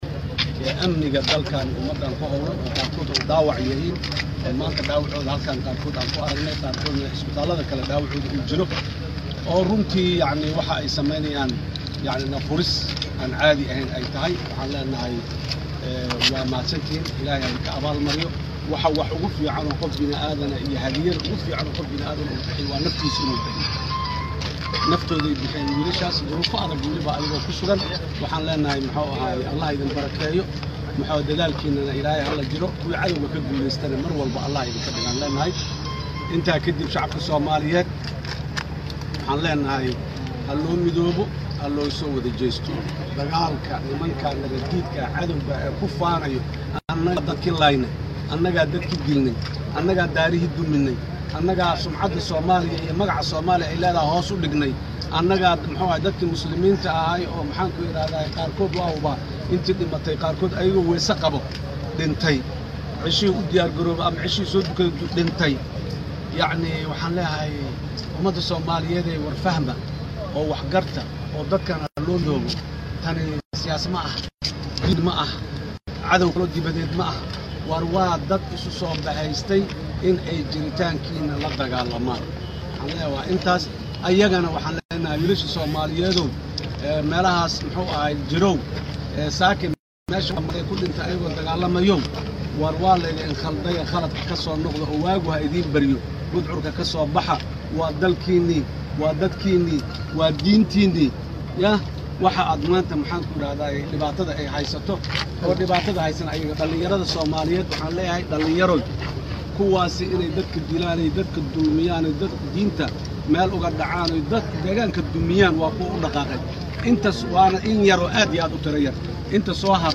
Dhageyso Codka Madaxweynihii hore ee Soomaaliya Xasan Sheekh
Codka-Xasan-Sheekh-Maxamuud.mp3